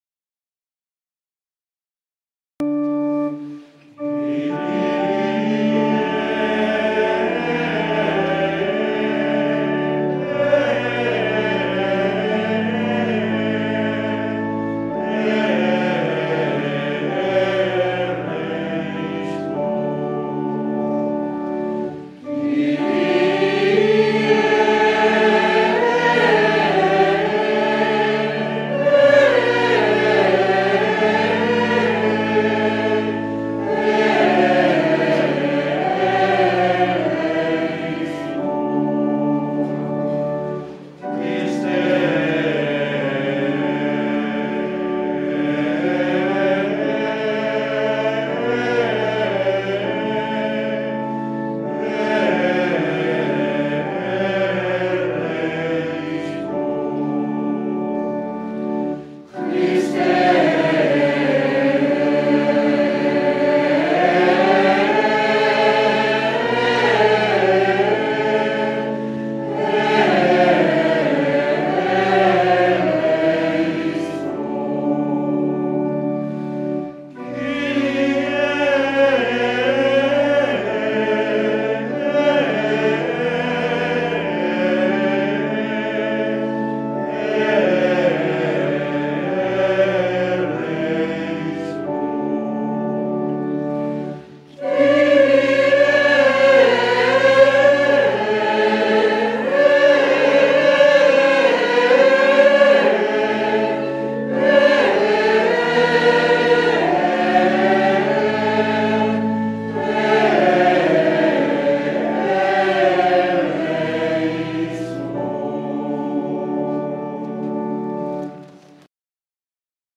Mass of the Angels, Gregorian Chant